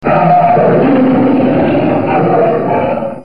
Dinosaur-Sound.mp3